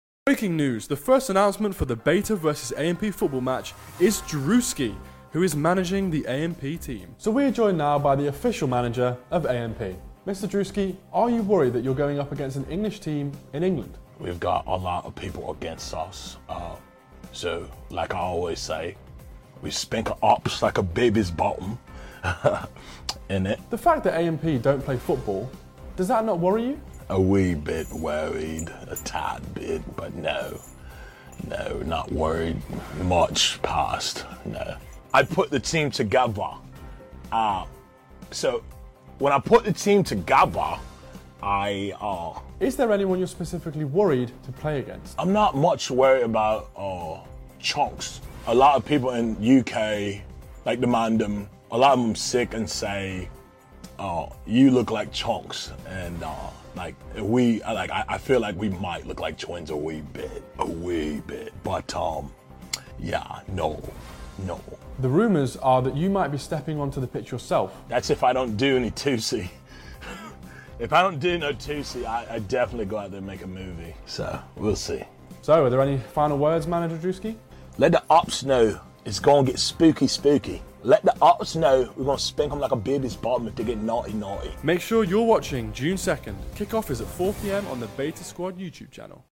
Rate druski UK accent 1 10 sound effects free download